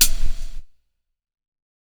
004_Lo-Fi Confident Hi-Hat.wav